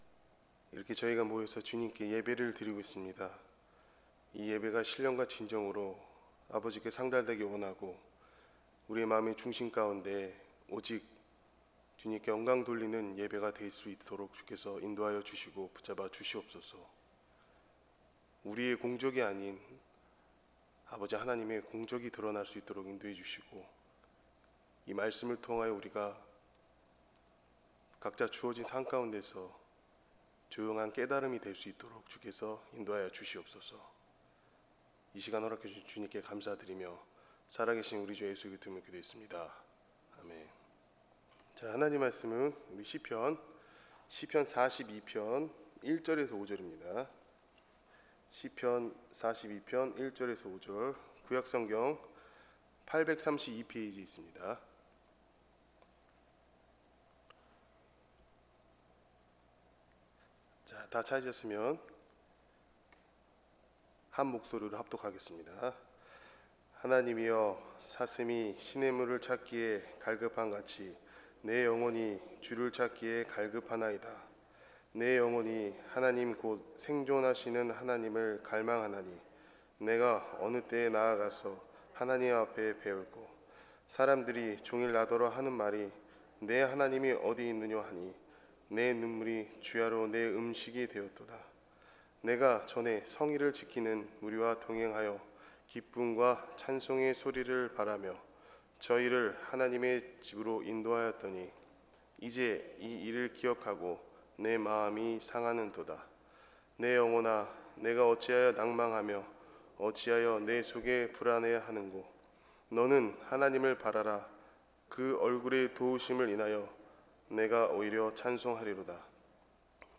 수요예배 시편 42장 1~5편